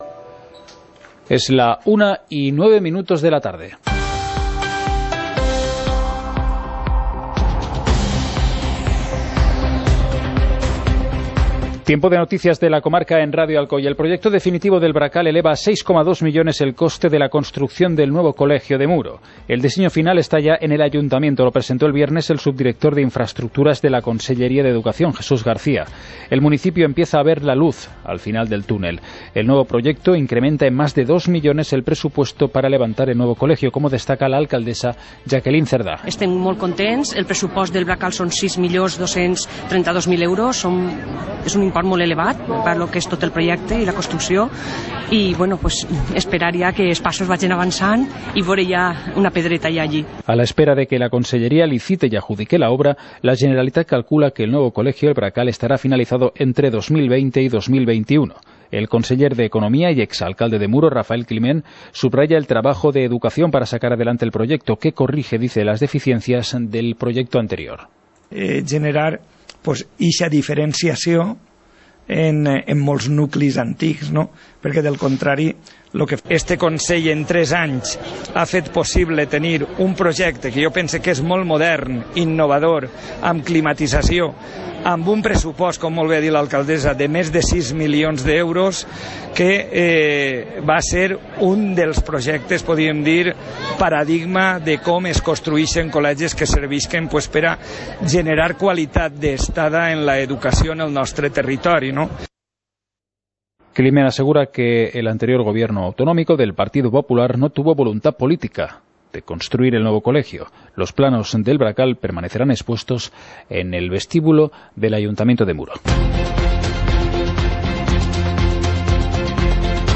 Informativo comarcal - lunes, 08 de octubre de 2018